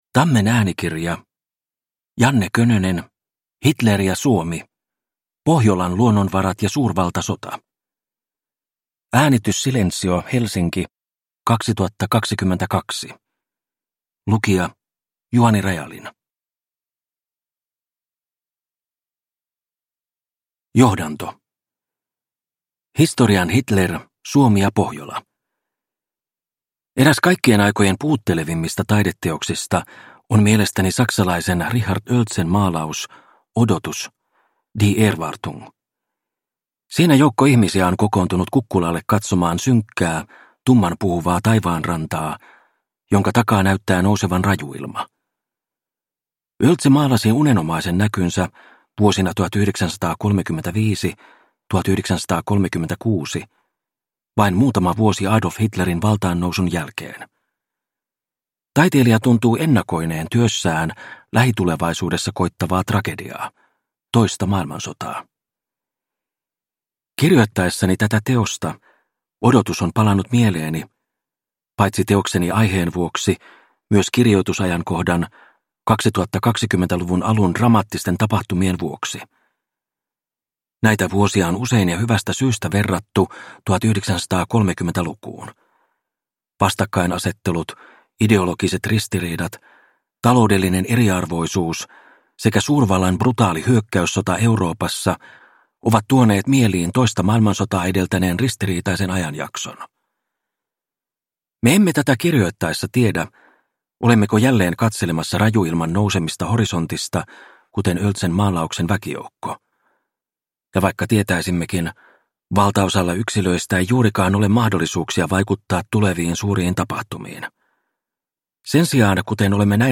Hitler ja Suomi – Ljudbok – Laddas ner